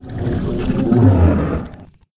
c_hydra_dead.wav